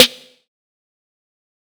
OZ-Snare (Hard).wav